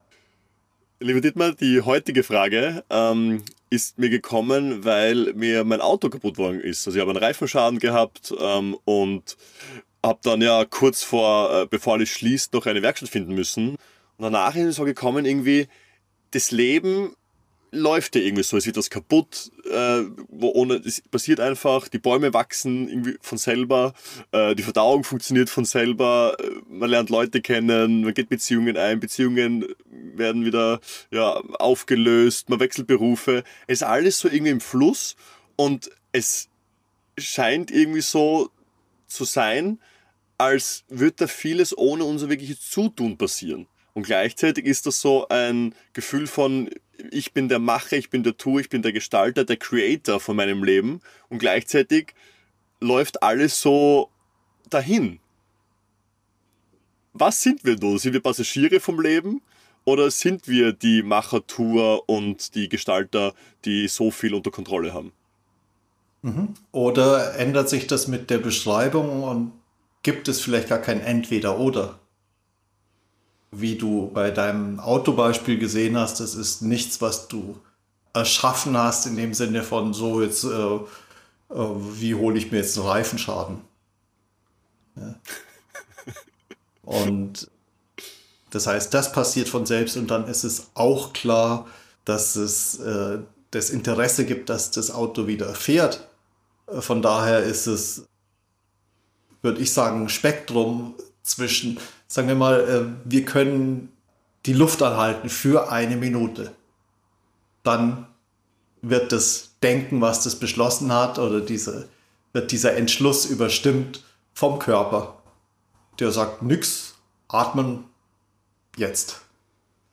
Bist du Entscheider in deinem Leben – oder geschieht das Leben von selbst, wie es in der Nondualität oft beschrieben wird? Im Gespräch hinterfragen wir dieses Entweder-oder: Gibt es das wirklich im direkten Erleben?